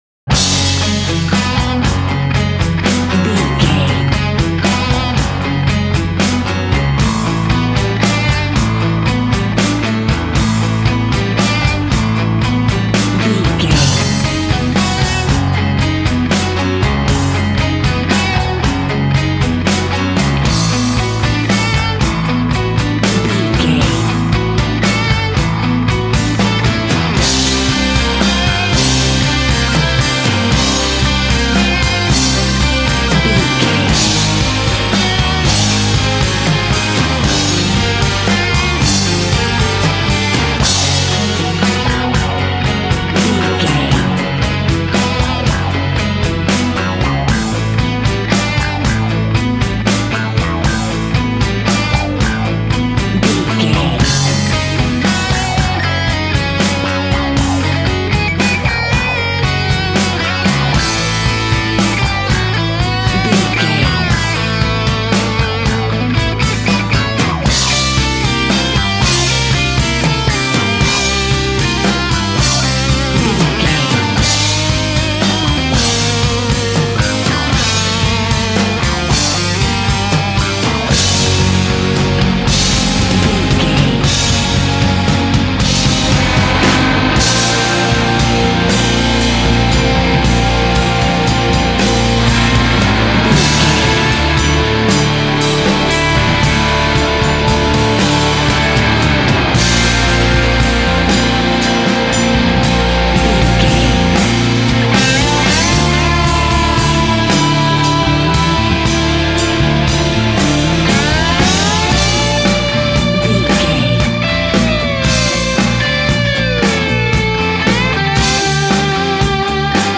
Ionian/Major
D
drums
electric guitar
bass guitar
synthesiser
Sports Rock
hard rock
lead guitar
aggressive
energetic
intense
powerful
nu metal
alternative metal